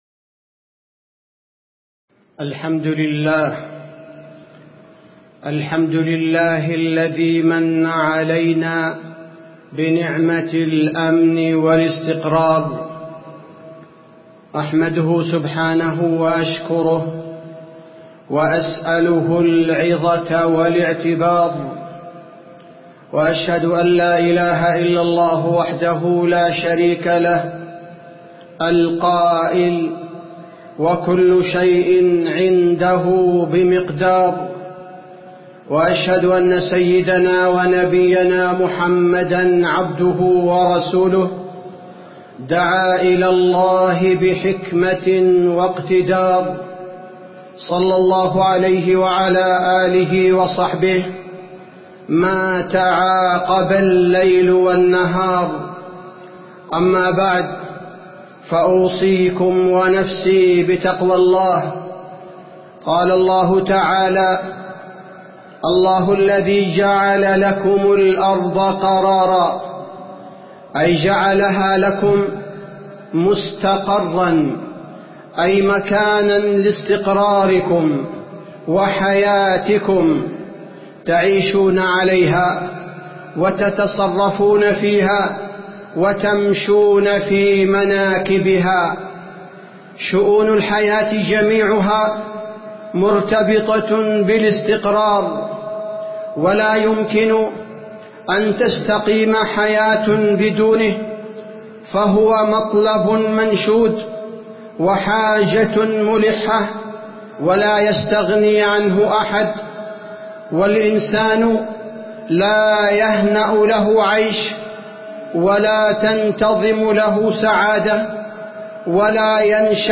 تاريخ النشر ١٠ شوال ١٤٣٧ هـ المكان: المسجد النبوي الشيخ: فضيلة الشيخ عبدالباري الثبيتي فضيلة الشيخ عبدالباري الثبيتي نعمة الأمن والاستقرار